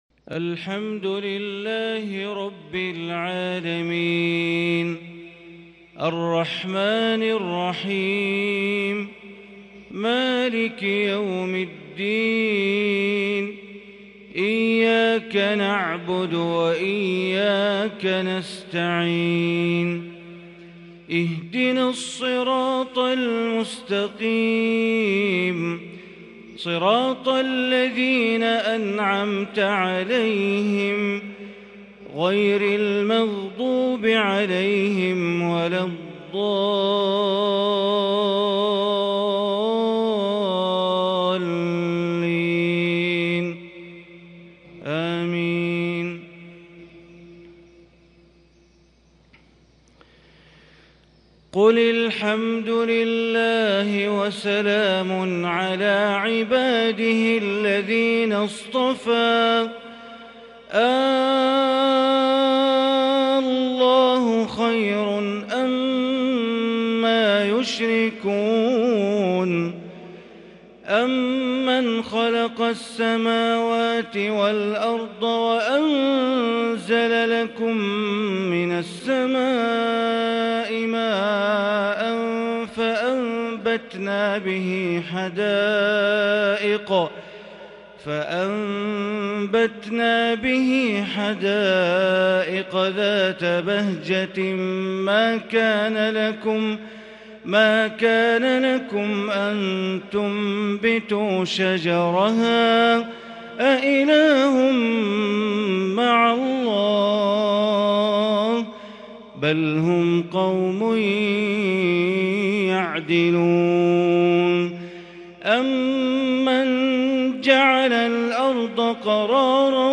فجر 1-4-1442 تلاوة من سورة النمل 59-93 > 1442 هـ > الفروض - تلاوات بندر بليلة